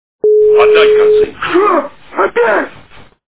При прослушивании Отдать концы! - Шо, опять? качество понижено и присутствуют гудки.